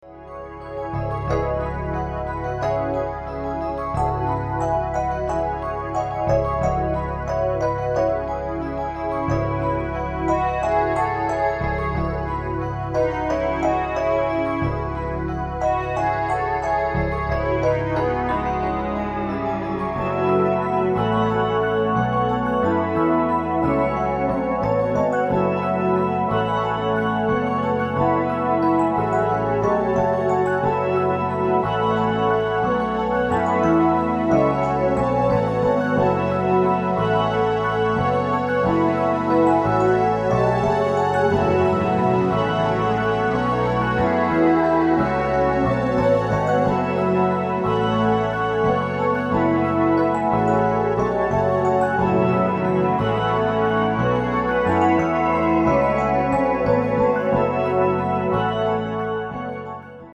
左右の耳から違う周波数の音を聞くことで、脳が各々に流れる周波数の差異を感知。
ココロはずませる音色が凹んだ気持ちをほがらかに